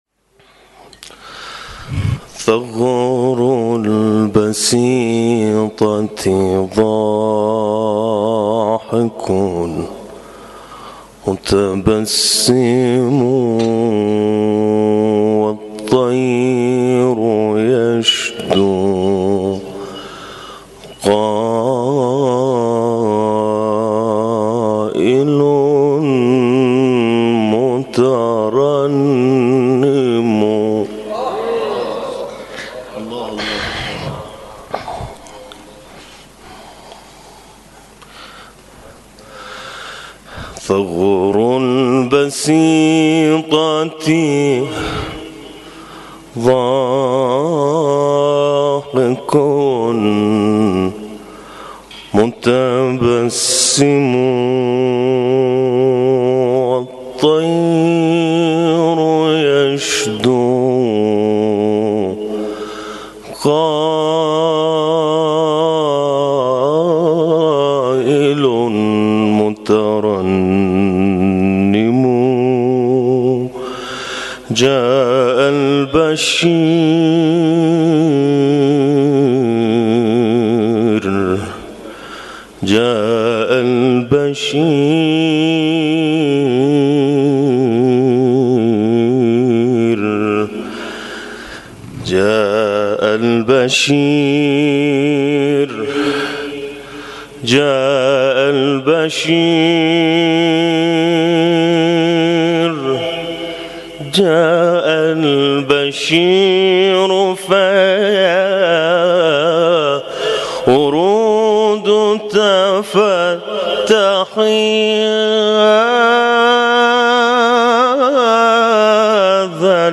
ابتهال
گروه شبکه اجتماعی ــ مبتهل و خواننده آواهای دینی در جلسه هفتگی مجمع قاریان قرآن کریم شهرری به اجرای اثر خود پرداخت.